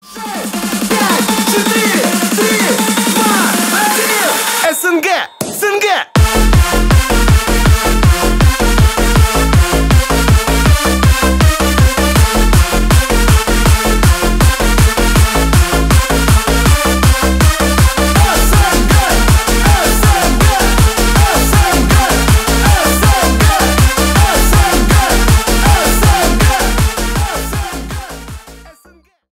Поп Музыка # Танцевальные
новогодние # весёлые